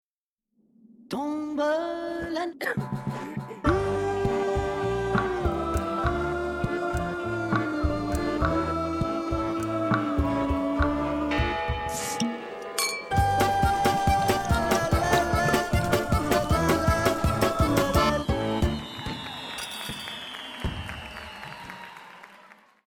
музыкальная шутка
на новогодний эфир